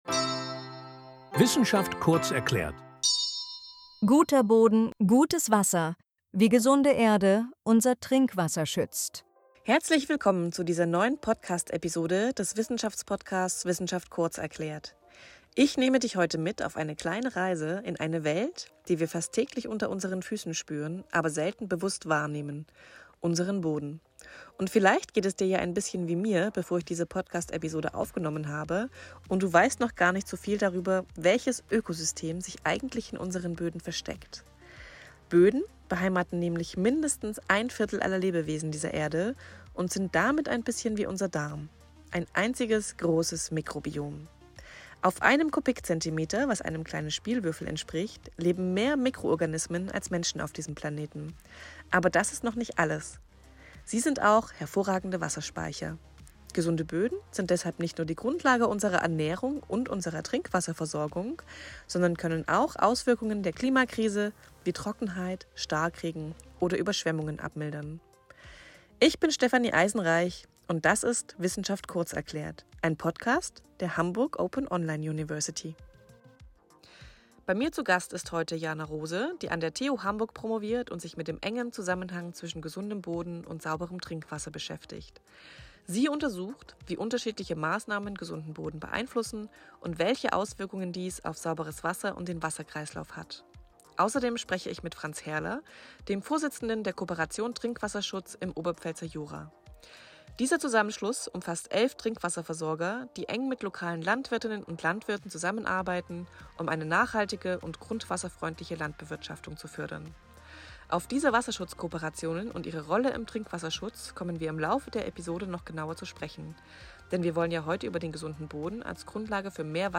In einem Gespräch mit zwei Expert*innen aus Wissenschaft und Praxis erfährst du, warum Böden in gutem Zustand die Grundlage für sauberes Trinkwasser sind – und welche einfachen Schritte du selbst im Alltag gehen kannst, um Boden und Wasser zu schützen.